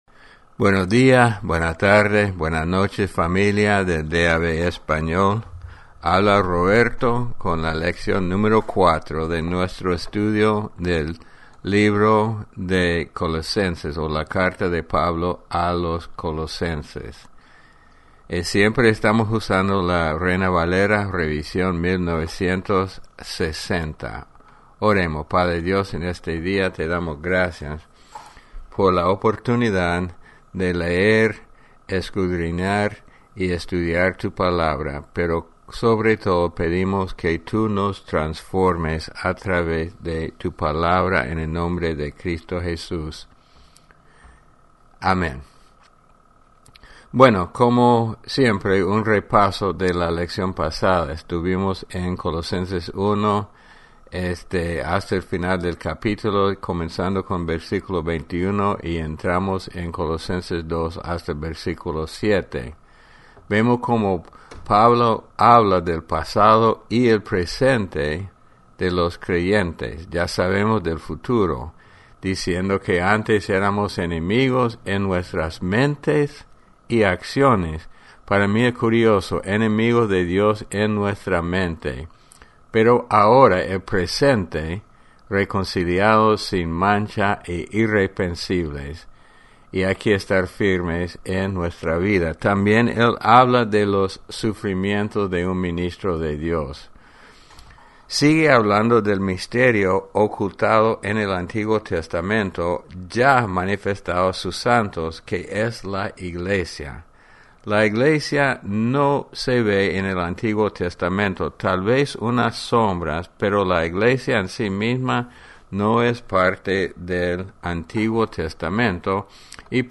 Descargar Audio Lección 4 Colosenses Repaso – la lección pasada – Colosenses 1 v.21-23 – Habla del pasado y el presente de los creyentes v.24-25 - Los sufrimientos de un ministro de Dios v.26-27 1.